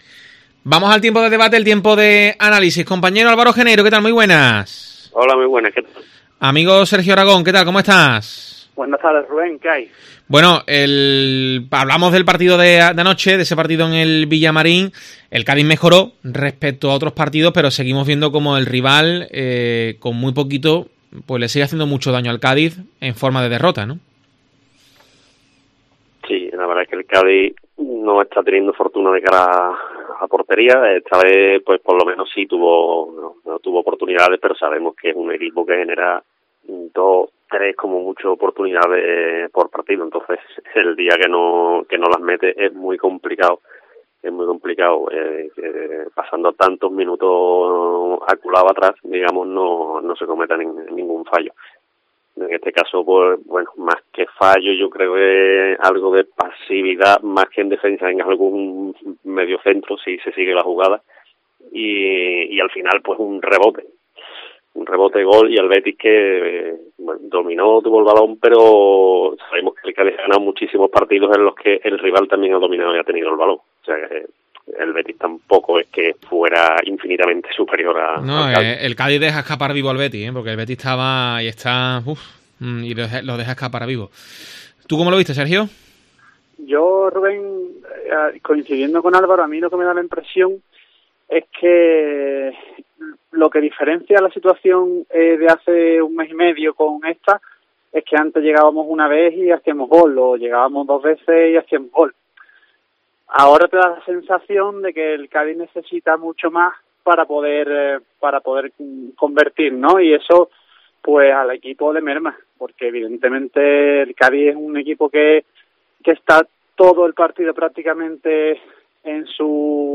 debate y opinión